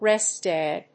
rést dày